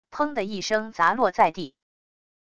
砰的一声砸落在地wav音频